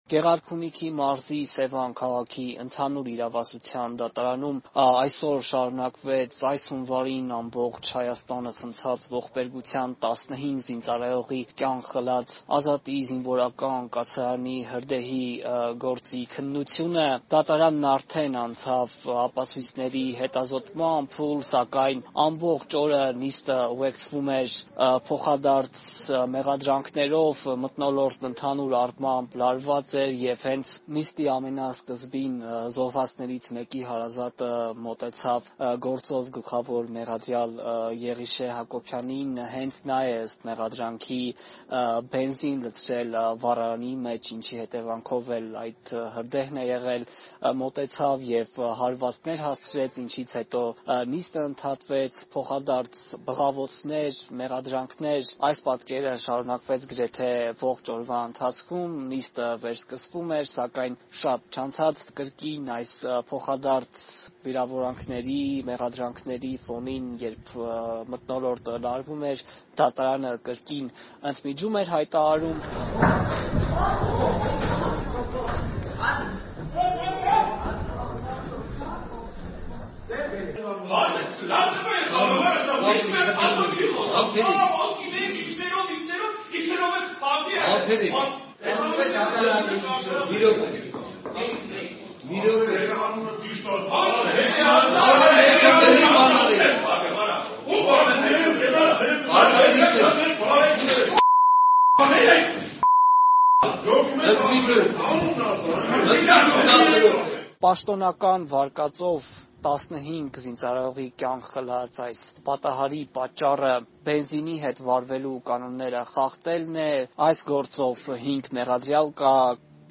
Մանրամասները՝ ռեպորտաժում.